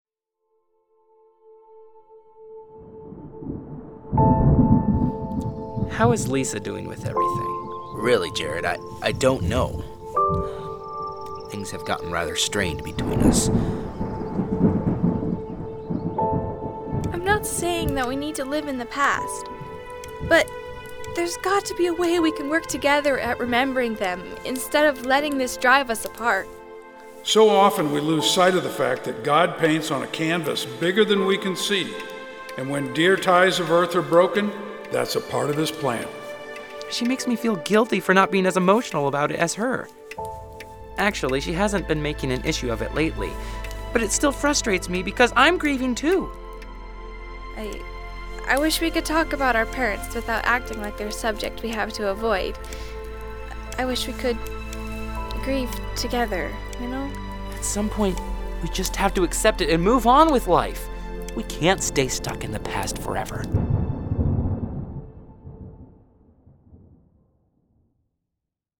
The-Painting-on-the-Canvas-Audio-Trailer.mp3